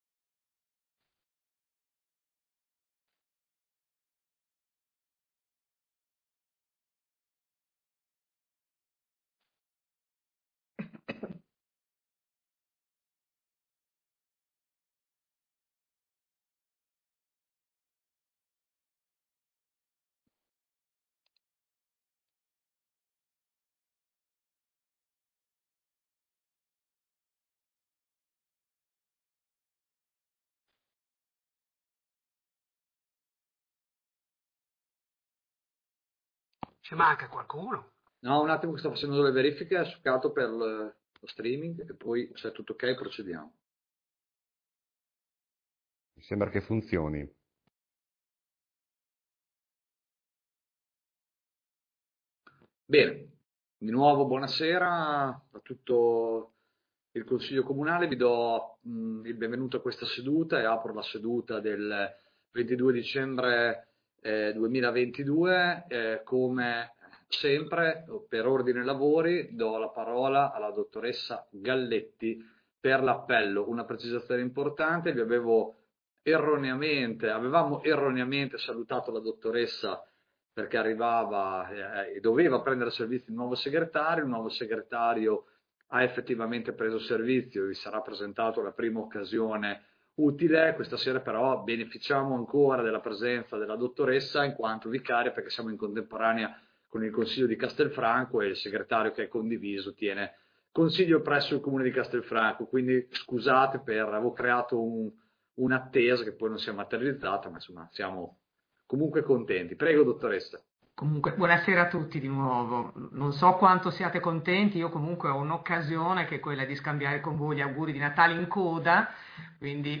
Consiglio Comunale del 22 dicembre 2022